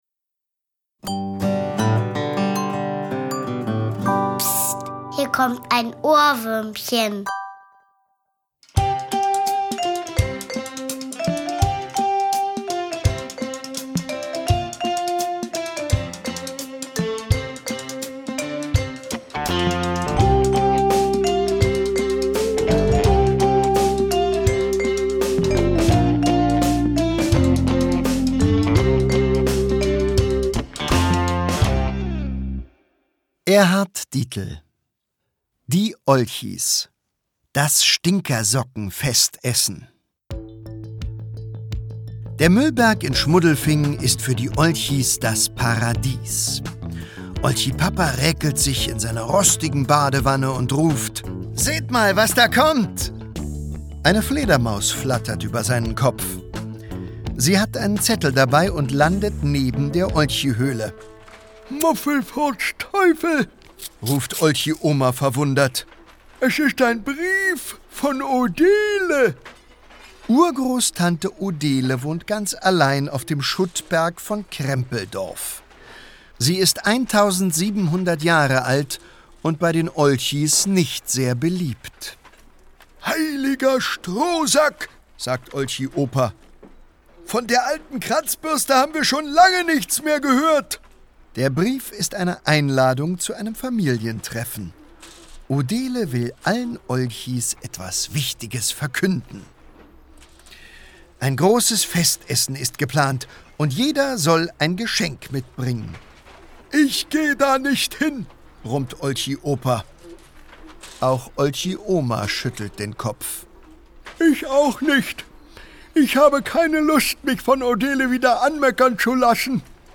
Hörbuch: Die Olchis.